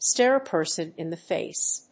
アクセントstáre a person in the fáce